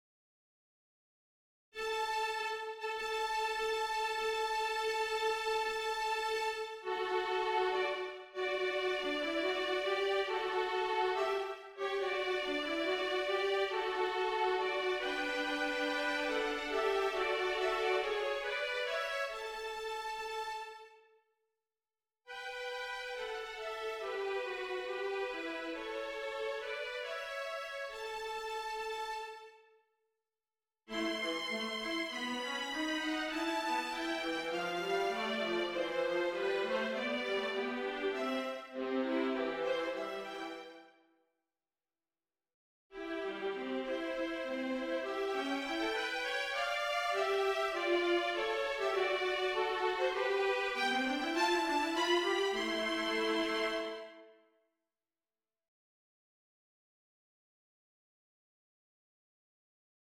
Wedding Melodies For Violin and Viola